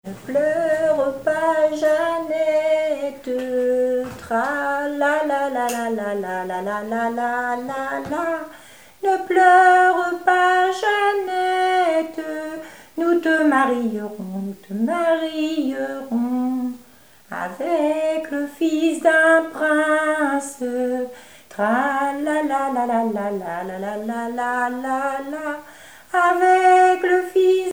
Genre laisse
Chansons et commentaires
Catégorie Pièce musicale inédite